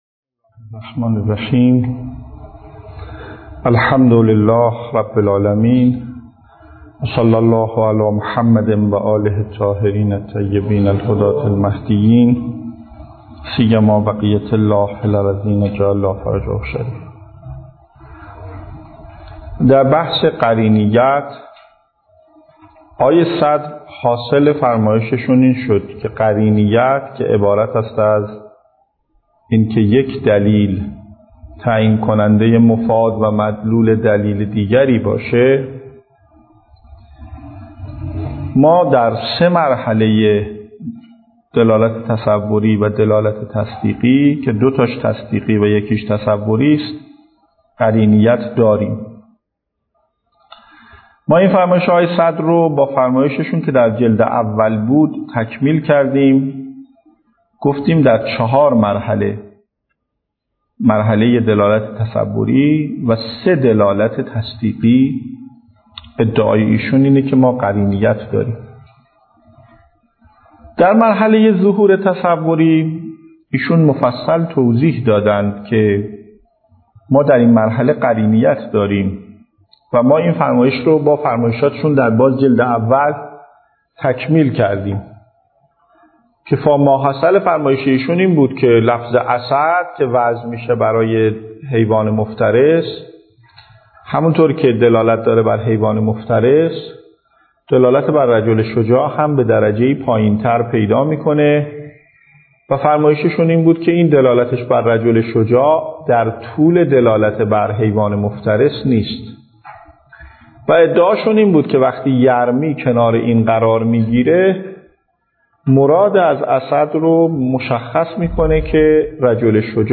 درس خارج اصول